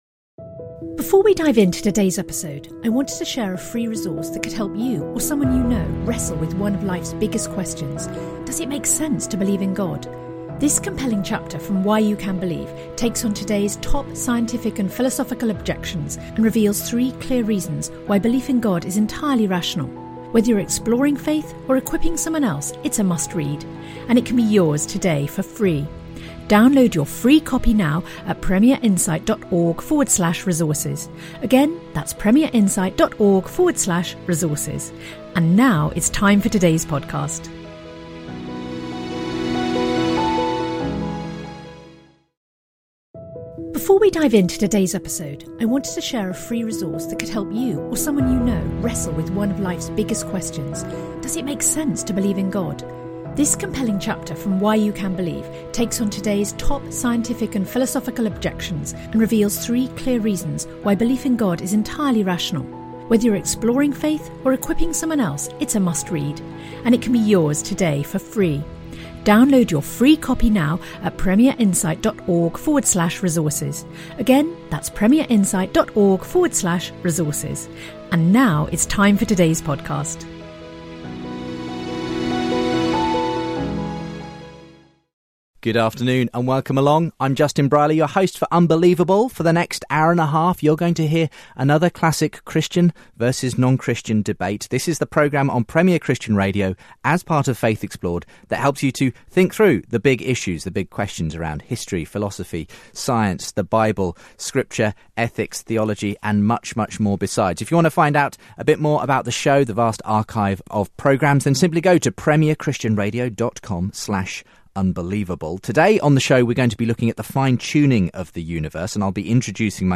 They debate the issues.